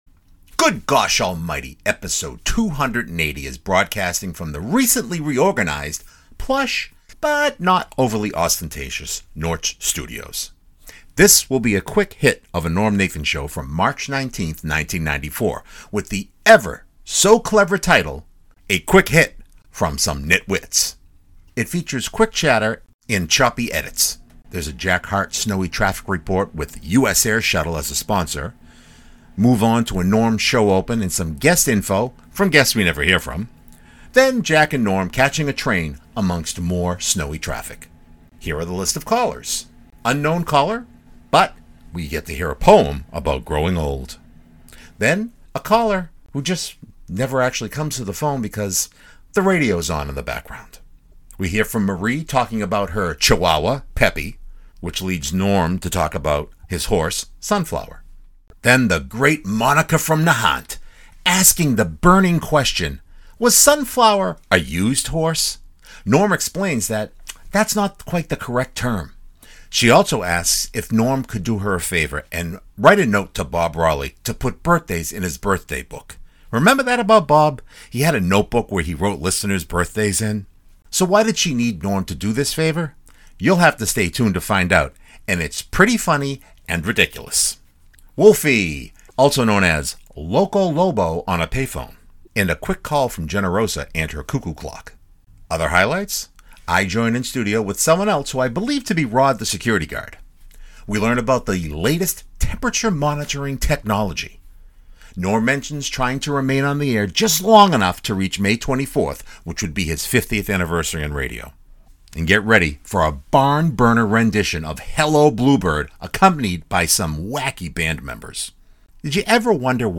This will be a quick hit of a NNS from March 19th, 1994, with the ever so clever title: A Quick Hit from Some Nit Wits It features quick chatter and choppy edits.
Caller: with radio on in background